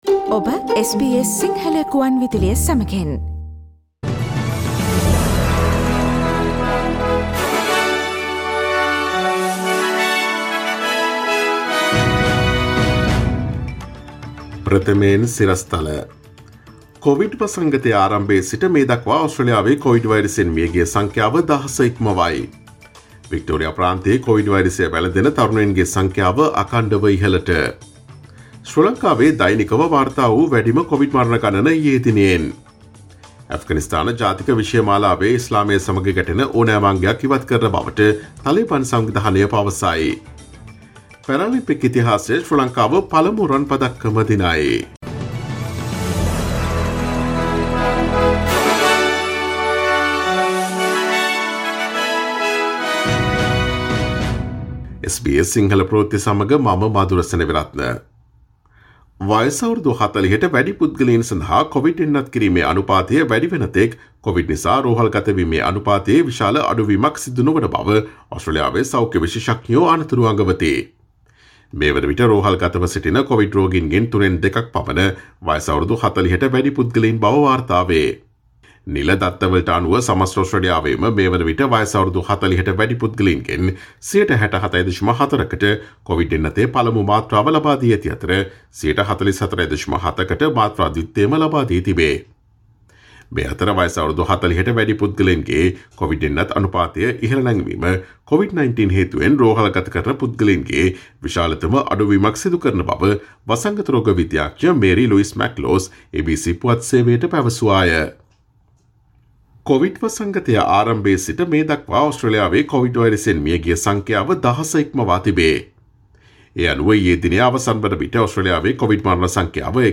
ඔස්ට්‍රේලියාවේ නවතම පුවත් මෙන්ම විදෙස් පුවත් සහ ක්‍රීඩා පුවත් රැගත් SBS සිංහල සේවයේ 2021 අගෝස්තු මස 31 වන දා අඟහරුවාදා වැඩසටහනේ ප්‍රවෘත්ති ප්‍රකාශයට සවන් දීමට ඉහත ඡායාරූපය මත ඇති speaker සලකුණ මත click කරන්න.